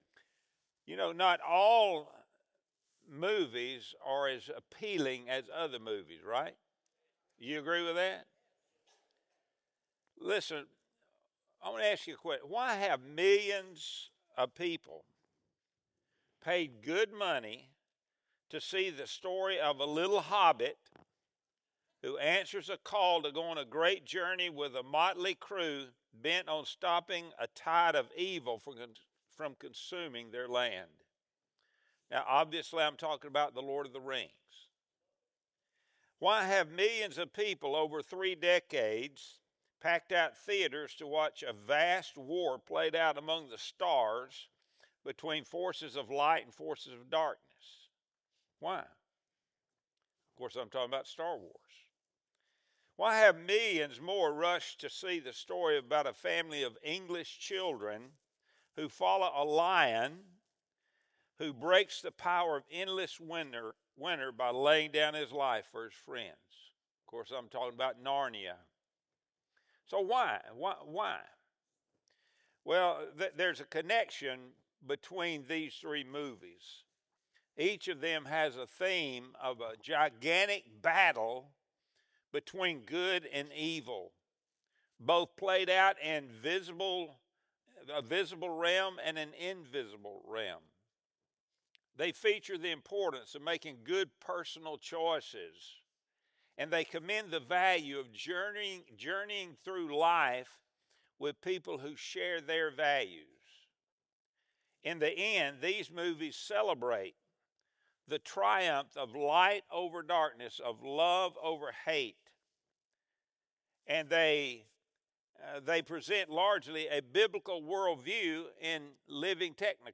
Wednesday Bible Study Series | January 7, 2025